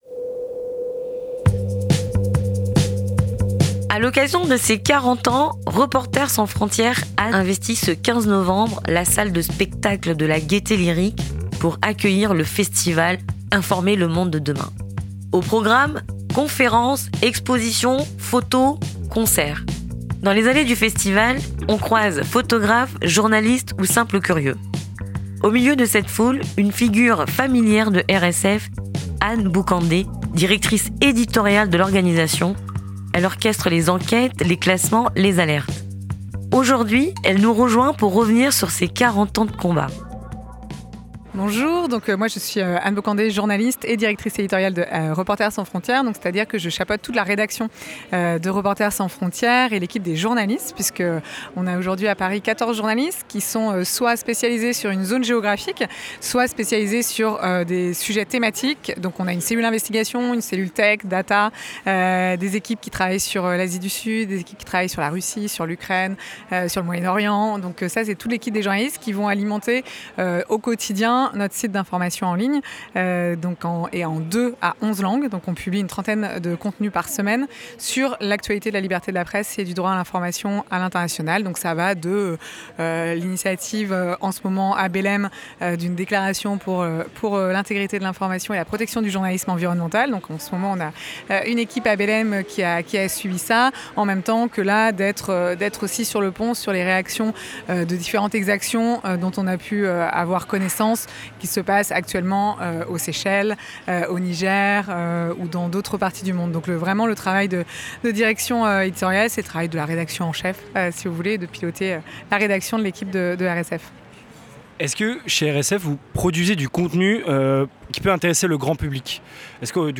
Reportage : Les 40 ans de Reporters sans frontière...
Retour sur le festival d'anniversaire de RSF à la Gaîté lyrique